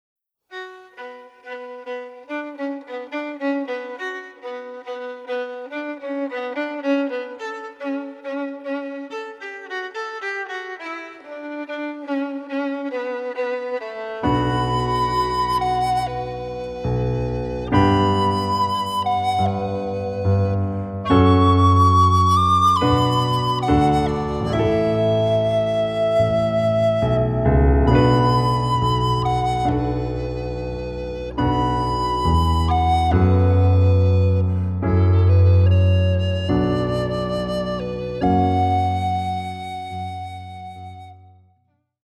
Blockflöte
Solovioline
Gitarre
Violoncello